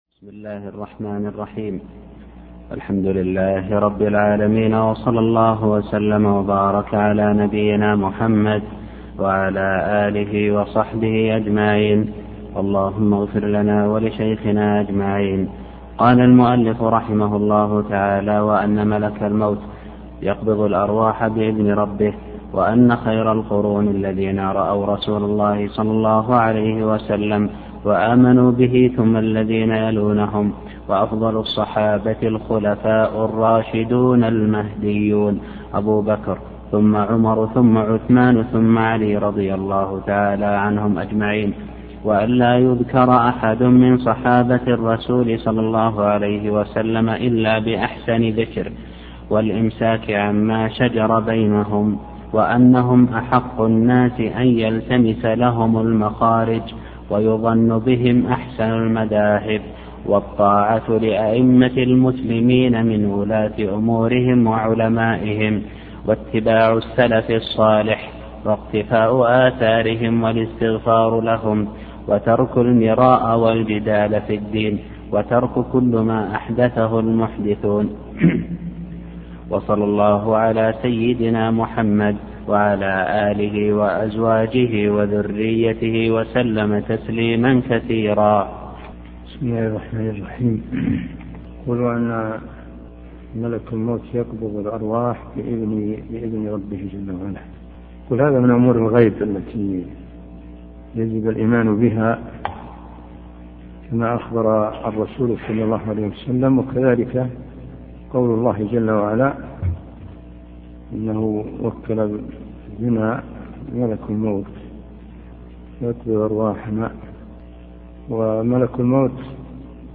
عنوان المادة الدرس (3) شرح مقدمة رسالة ابن أبي زيد القيرواني تاريخ التحميل الأثنين 27 فبراير 2023 مـ حجم المادة 26.46 ميجا بايت عدد الزيارات 157 زيارة عدد مرات الحفظ 76 مرة إستماع المادة حفظ المادة اضف تعليقك أرسل لصديق